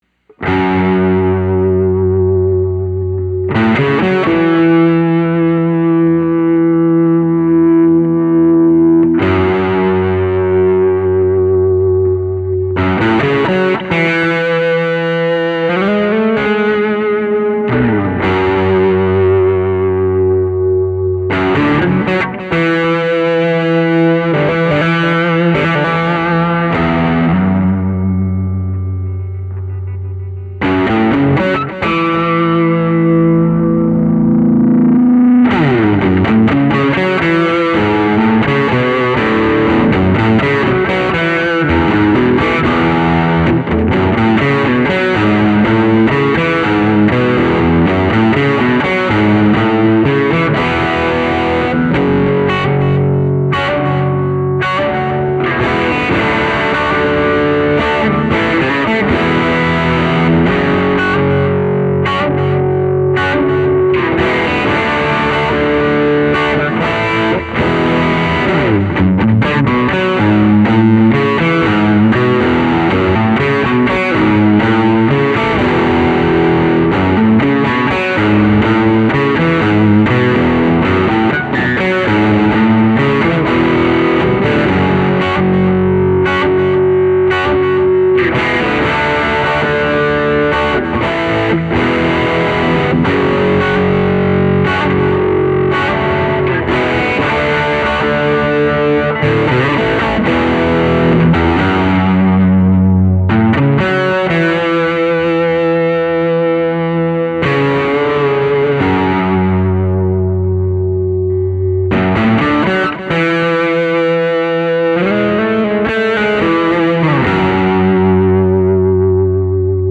VINTAGE 1950s Gibson ES-225 Thinline Guitar
Here are 5 quick, 1-take MP3 sound files of myself playing this guitar, to give you an idea of what to expect. The guitar has great tone, sustain, and body, and is also capable of some nice controlled feedback effects. These tracks are all recorded using the HB position, playing through a Peavey Studio Pro 112 amp with a Behringer Virtualizer effects unit, using a Shure SM57 mic recorded straight into a Sony PCM D1 flash recorder, and MP3s were made in Logic, with no EQ or effects.
(Original, in E)